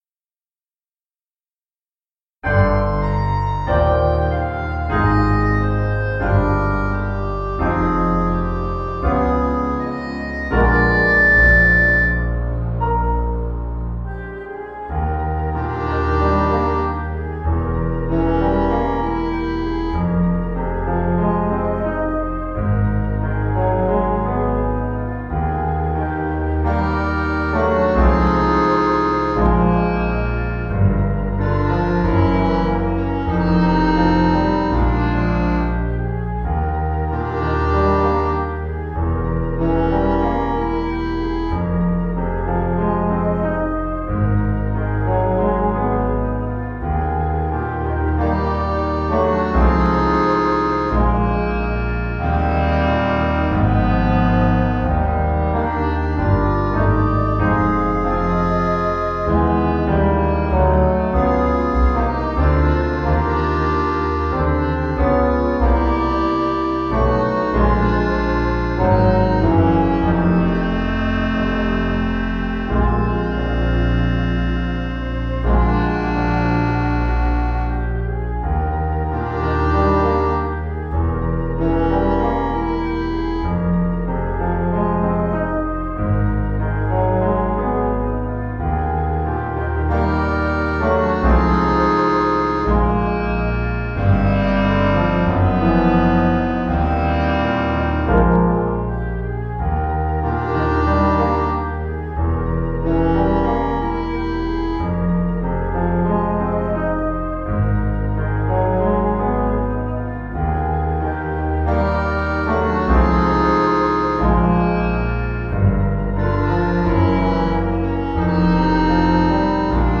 RETURN TO INDEX Music for Download VA = Virtual Accompaniment TILL THERE WAS YOU (SOLO) Sheet Music VA Till-there-was-you-Eb-VA.mp3 rehearsal recording rehearsal recording rehearsal recording YouTube